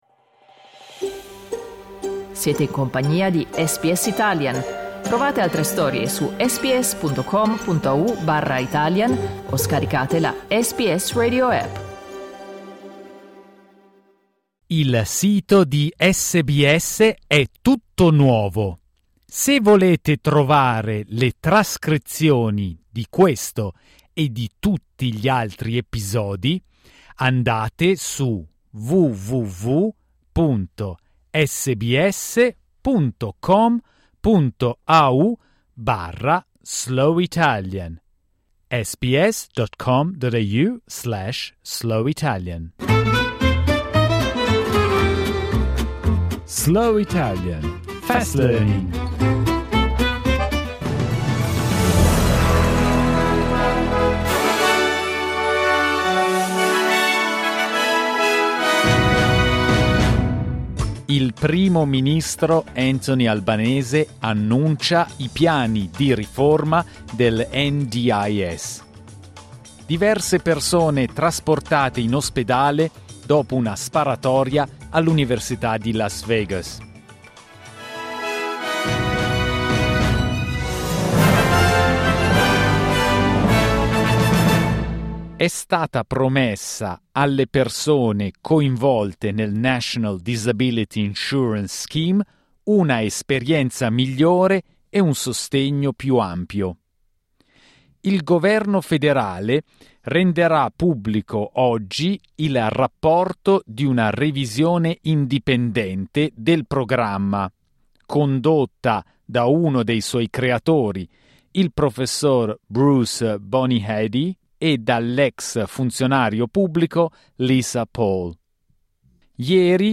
SBS Italian's News bulletin, read slowly.